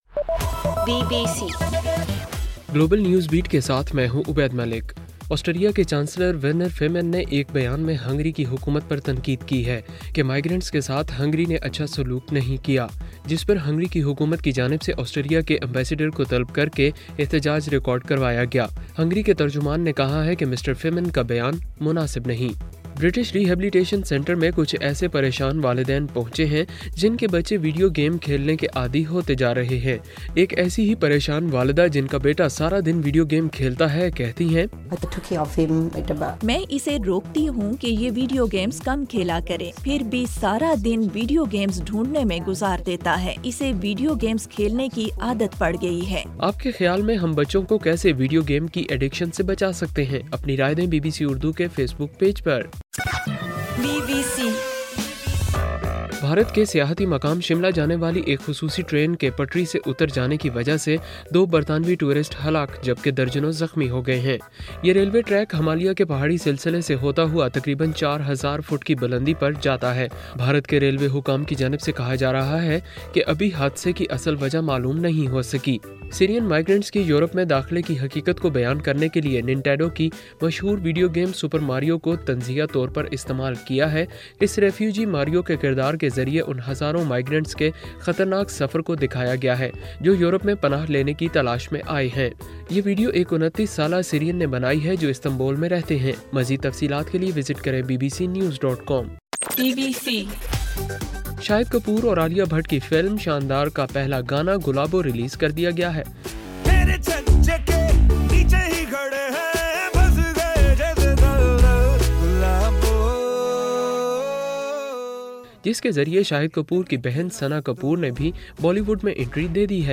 ستمبر 12: رات 12 بجے کا گلوبل نیوز بیٹ بُلیٹن